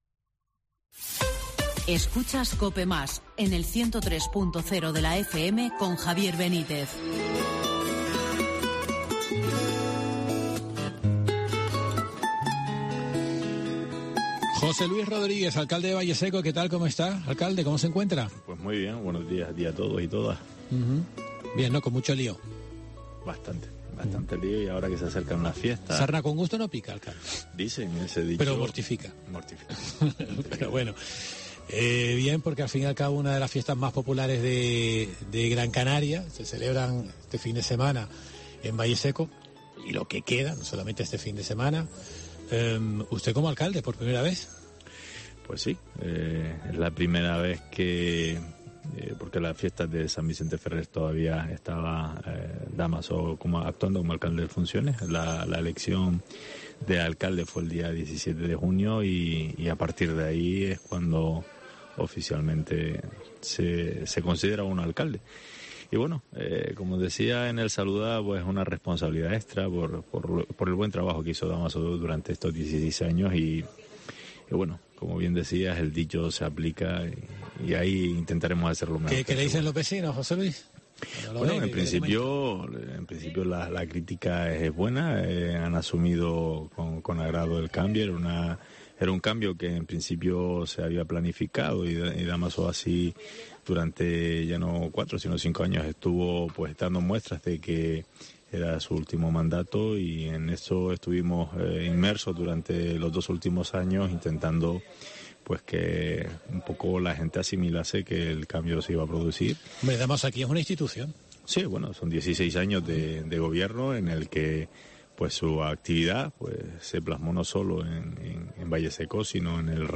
José Luís Rodríguez, alcalde Valleseco